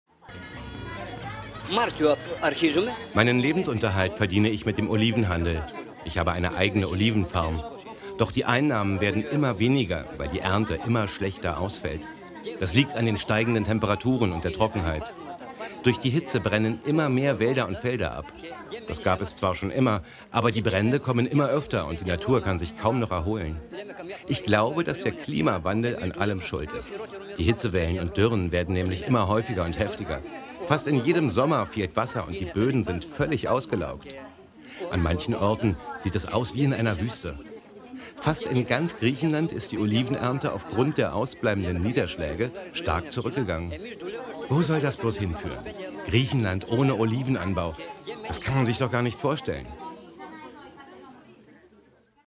Olivenbauern erzählen: